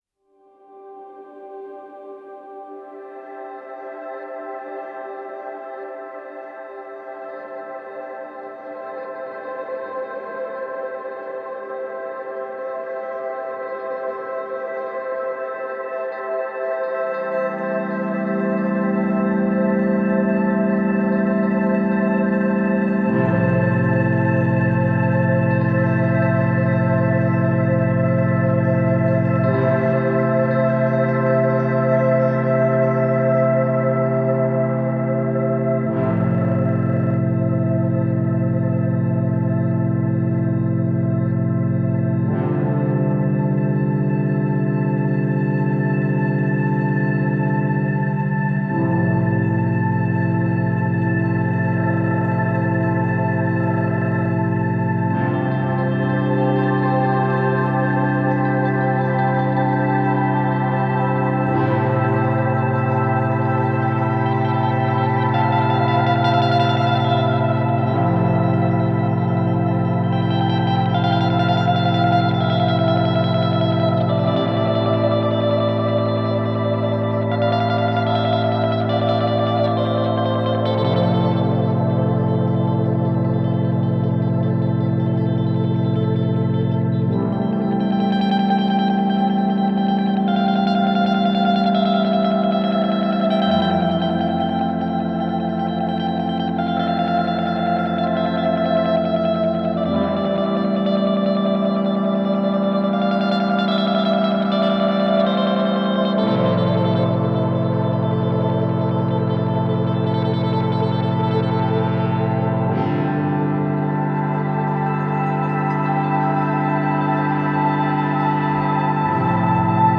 Shimmering synths, textured guitar and deep bass.